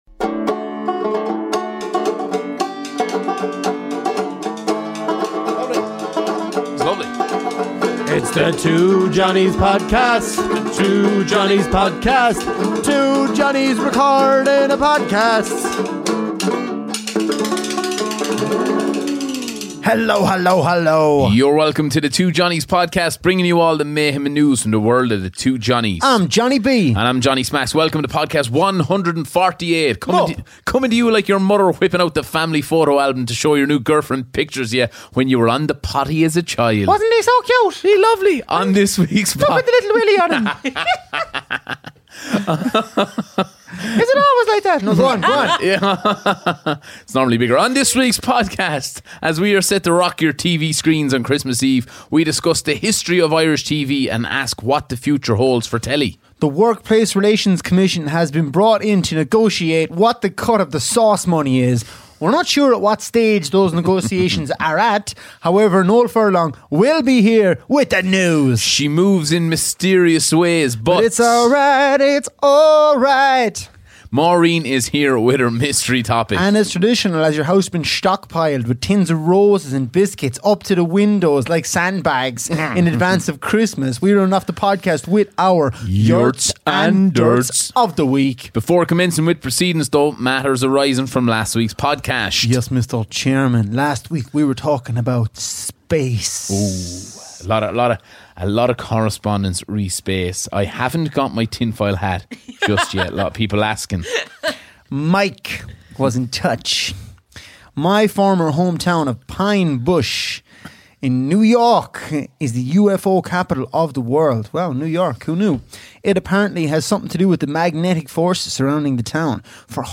Ireland's favourite comedy duo tackle the big issues, this week: